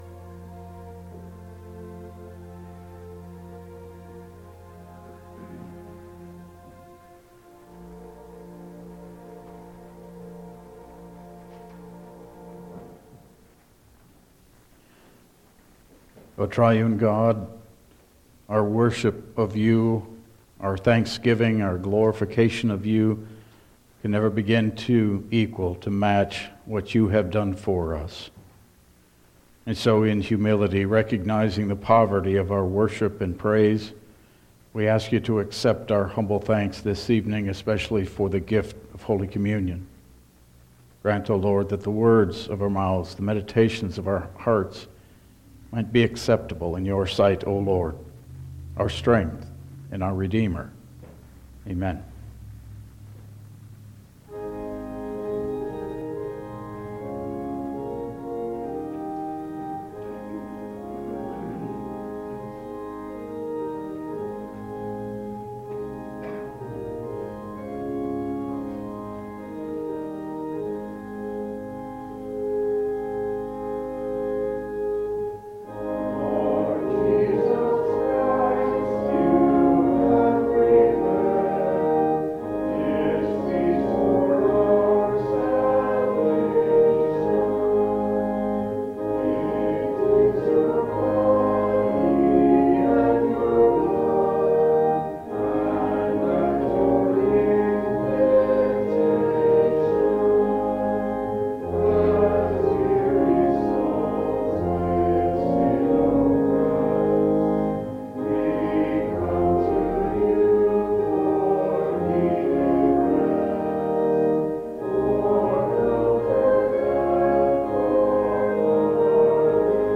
Service Type: Lenten Service